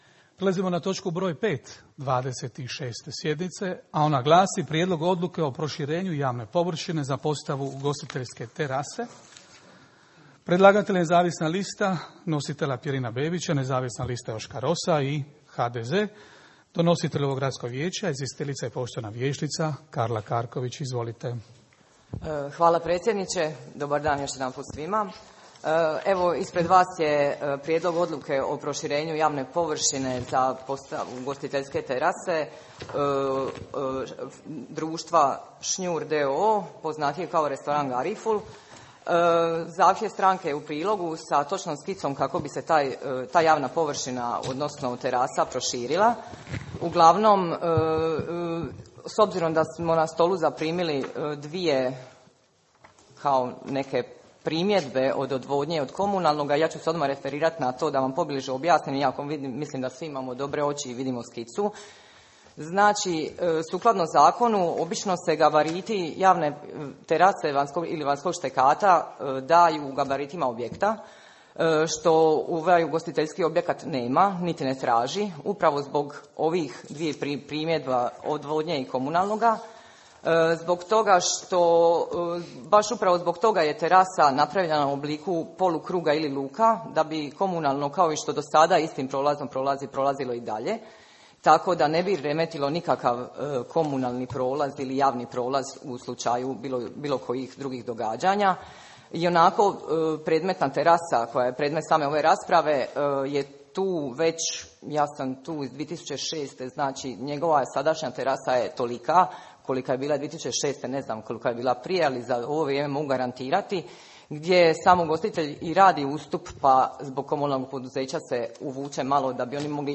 Snimka 26. sjednice Gradskog vijeća Grada Hvara
Audiozapise 26. sjednice Gradskog vijeća Grada Hvara održane 6. lipnja 2023. možete poslušati na poveznicama.